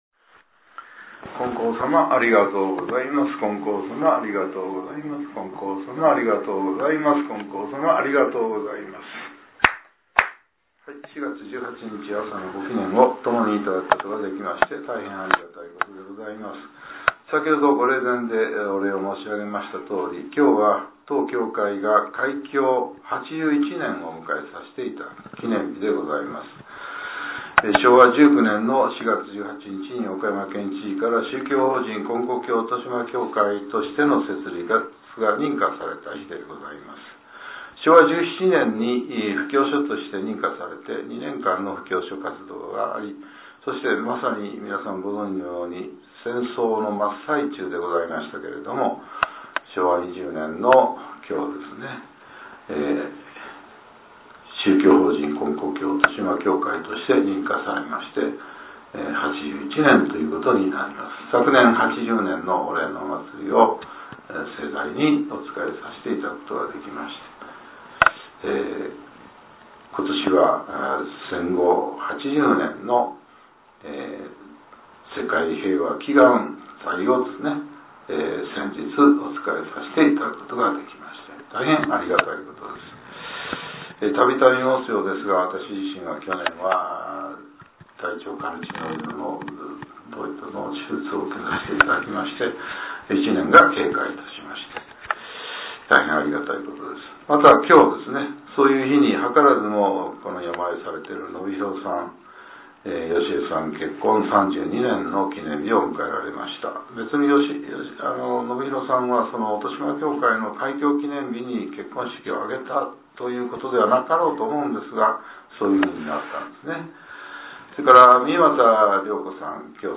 令和７年４月１８日（朝）のお話が、音声ブログとして更新されています。